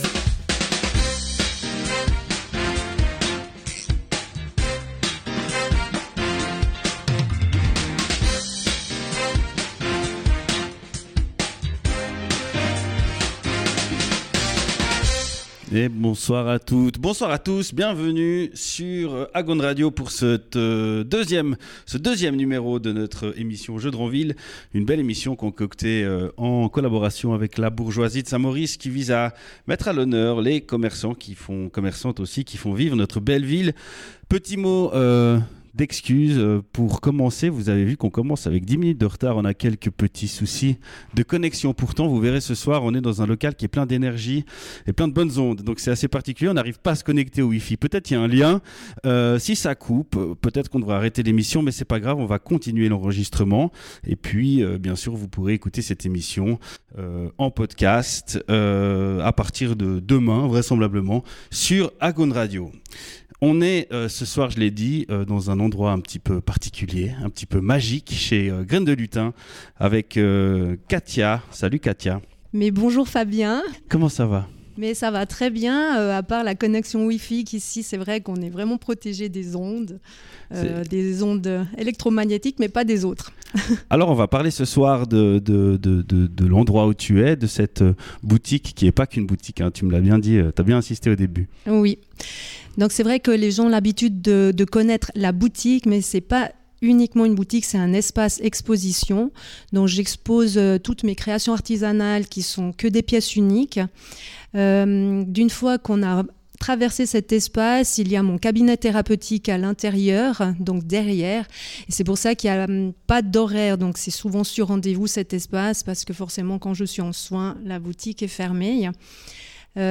Jeudr'en ville était à l'Espace Graine de Lutin